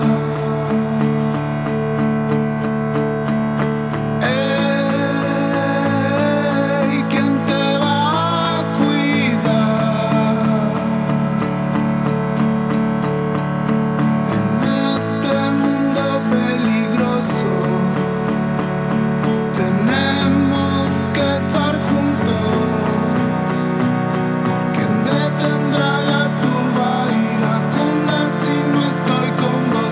Жанр: Поп музыка / Альтернатива